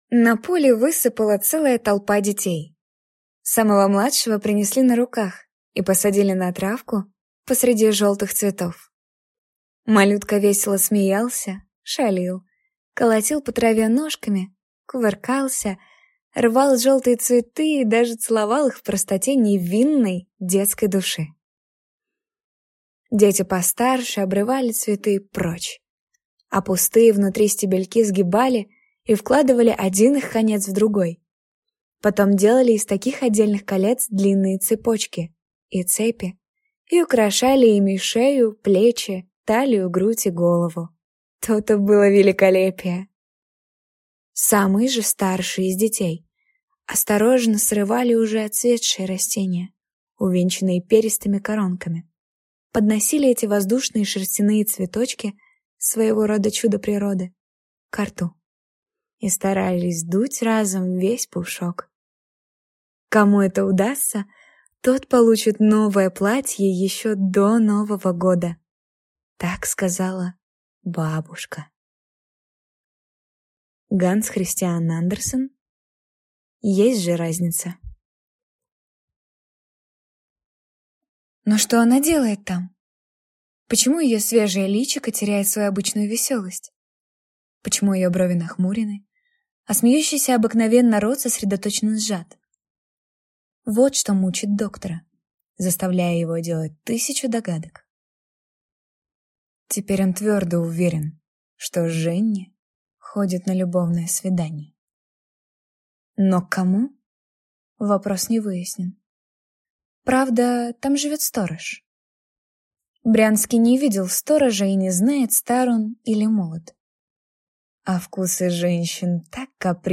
Пример звучания голоса
Жен, Аудиокнига/Молодой